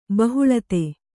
♪ bahuḷate